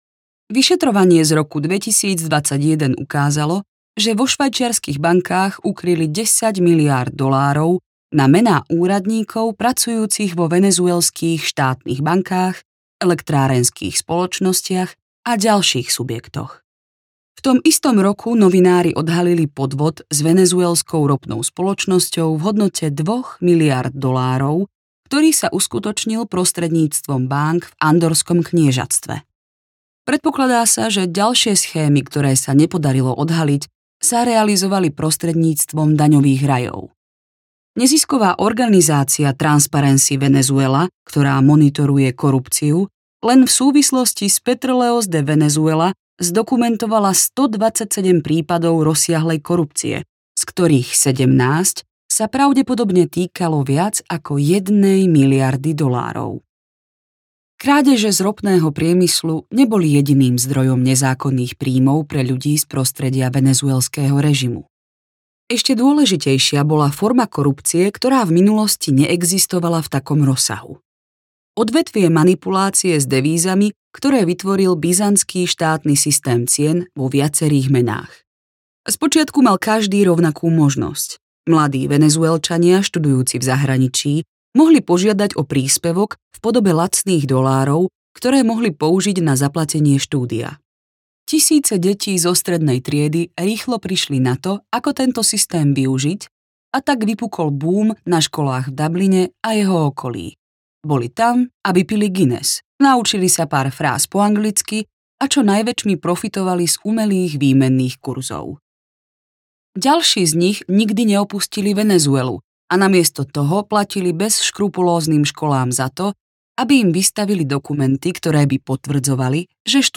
Autokracia, s.r.o. audiokniha
Ukázka z knihy